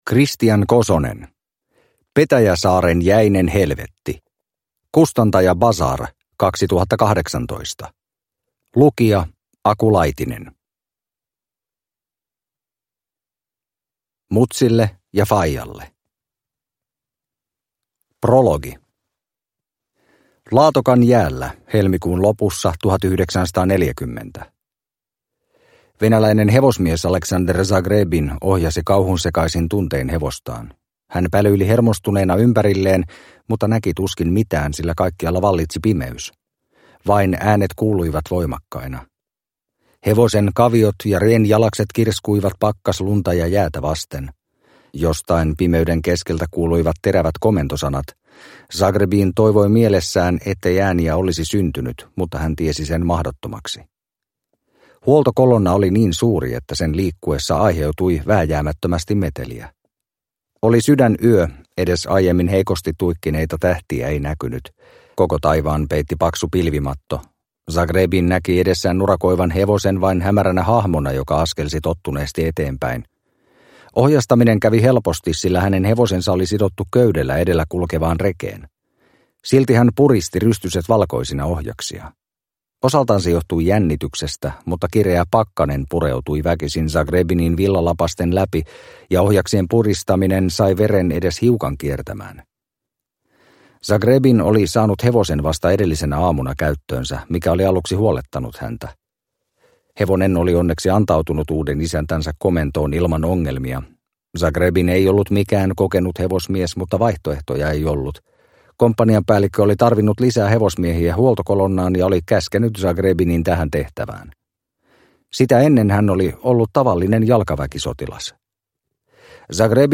Petäjäsaaren jäinen helvetti – Ljudbok – Laddas ner